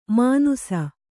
♪ mānusa